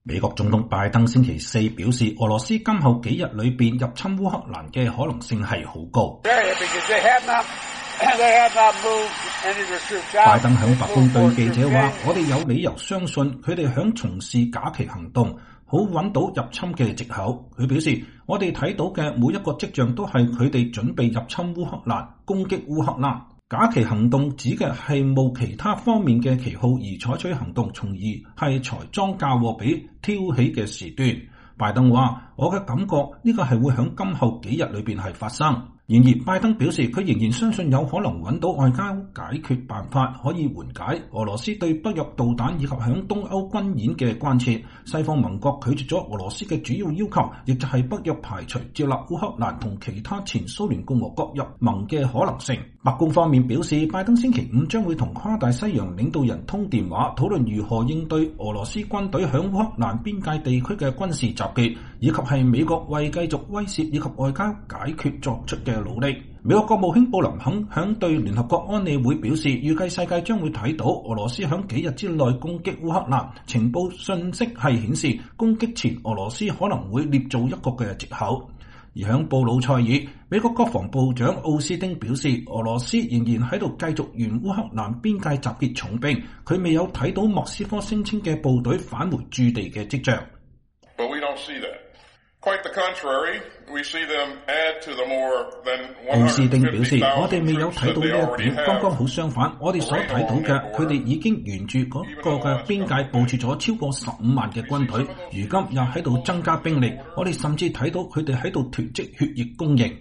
拜登總統在前往俄亥俄州推動他的基礎設施建設議程之前在白宮南草坪對媒體講話。(2022年2月17日)